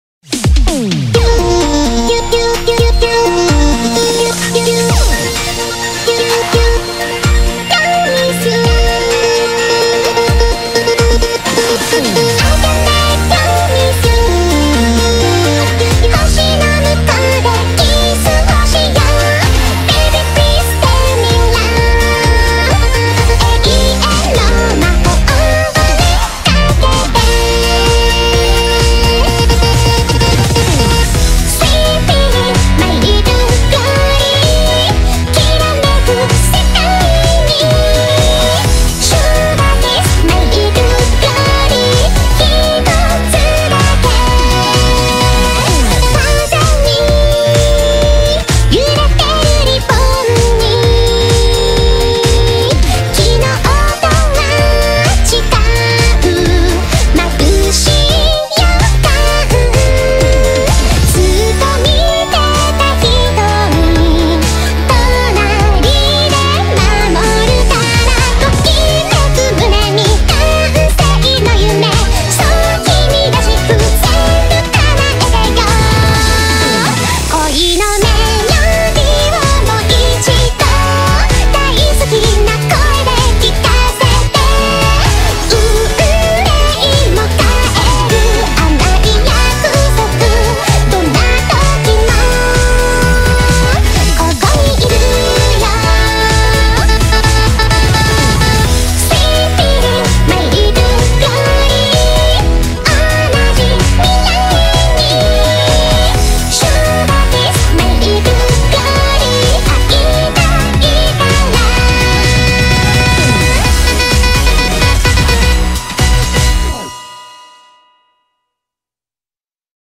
BPM64-128
Audio QualityCut From Video